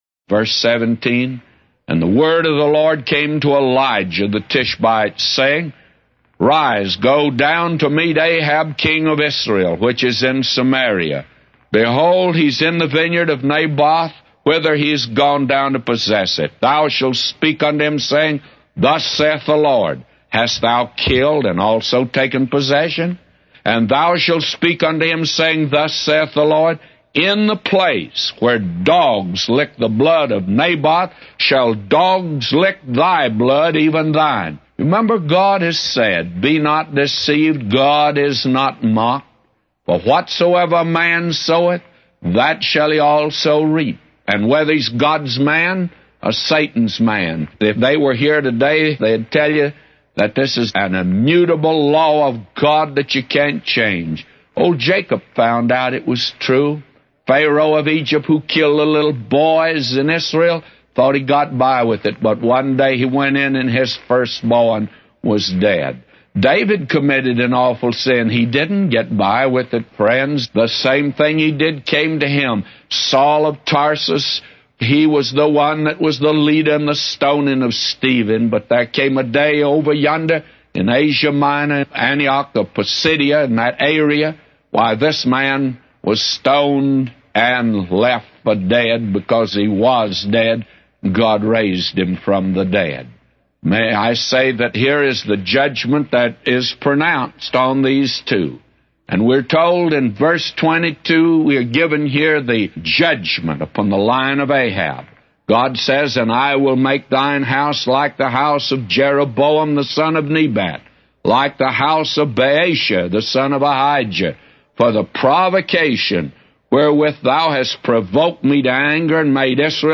A Commentary By J Vernon MCgee For 1 Kings 21:17-999